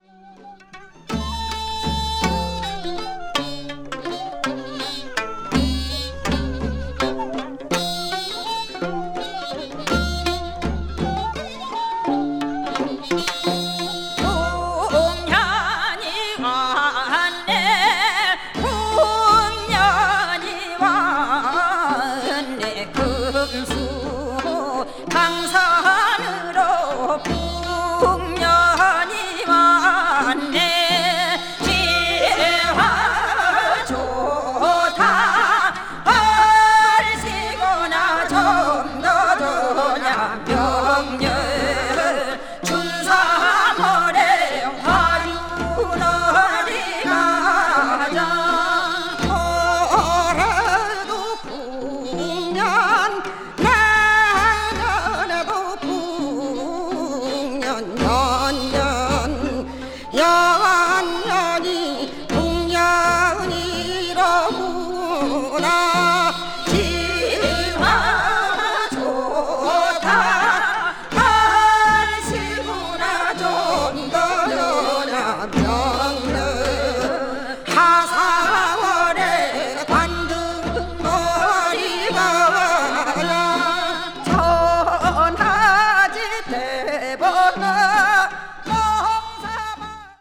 media : EX/EX(わずかにチリノイズが入る箇所あり)
粘り気と伸びのあるドスの効いた超絶的歌唱力がまったくもって素晴らしいです。
east asia   ethnic music   folk   korea   south korea   traditional